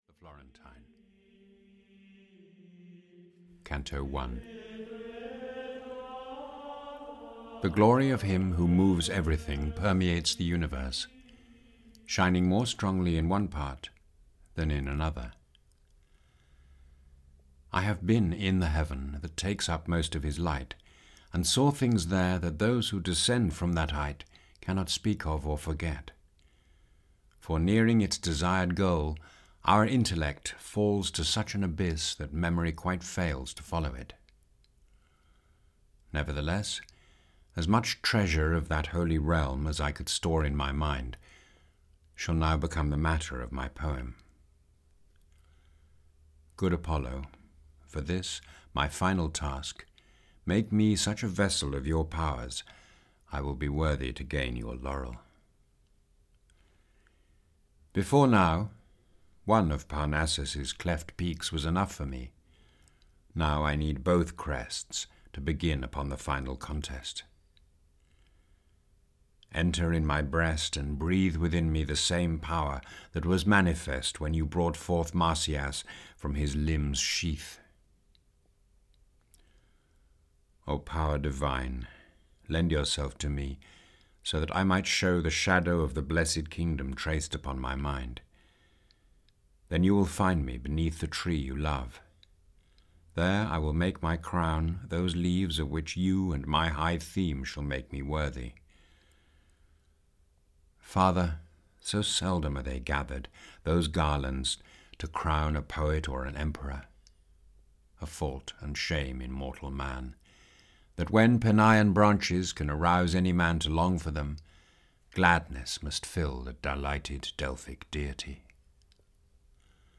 Paradise (EN) audiokniha
Ukázka z knihy
• InterpretHeathcote Williams